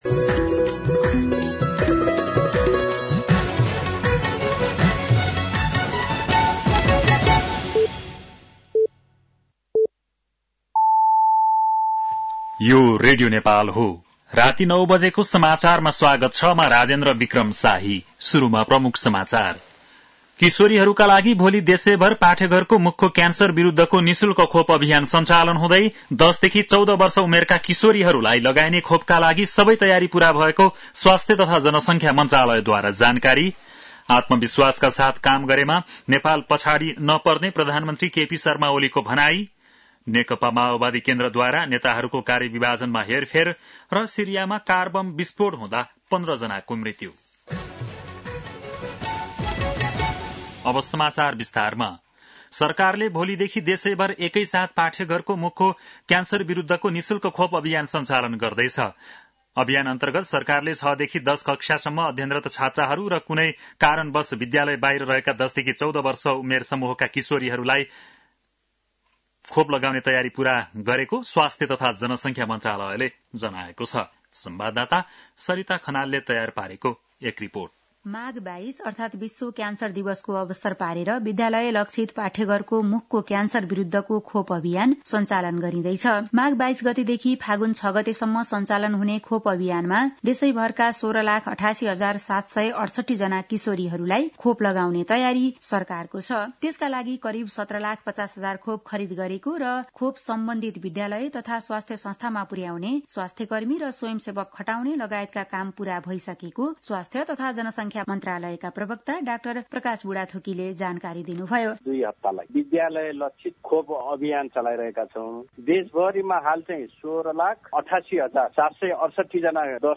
बेलुकी ९ बजेको नेपाली समाचार : २२ माघ , २०८१
9-PM-Nepali-NEWS-10-21.mp3